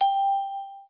SFX音效
SFX按钮点击叮咚音效下载